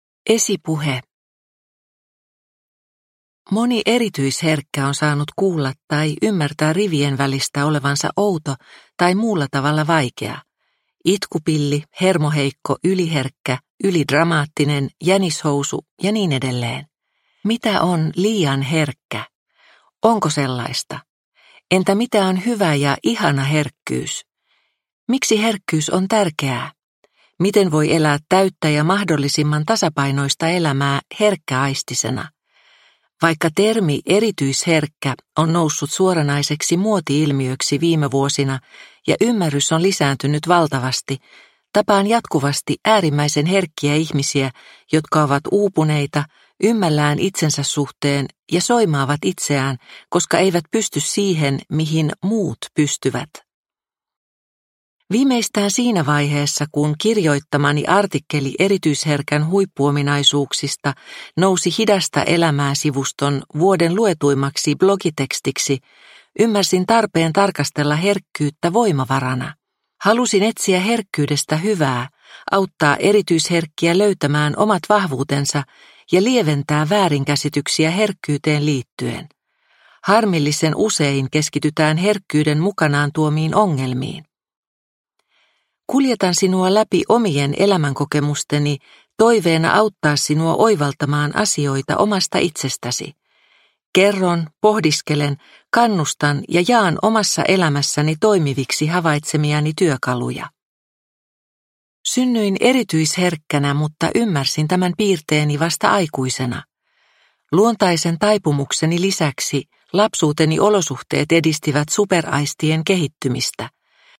Ihana herkkyys – Ljudbok – Laddas ner